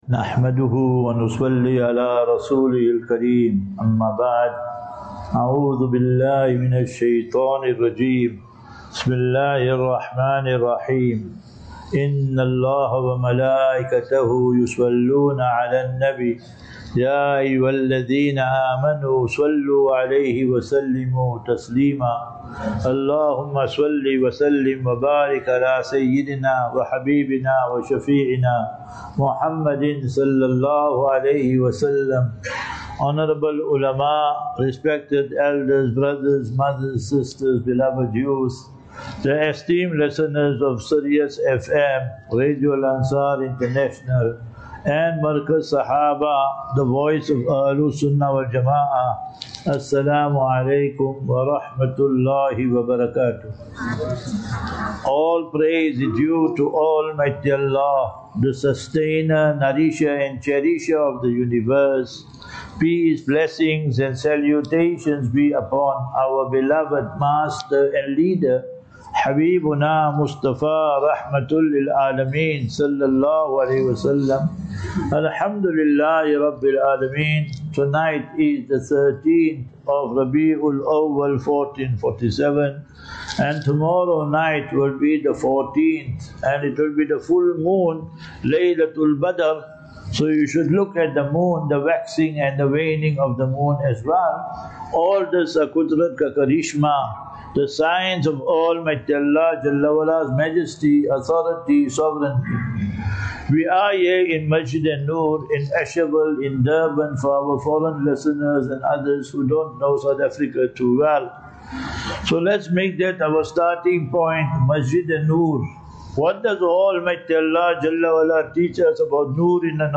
5 Sep 05 September 2025 - KZN Lecture Series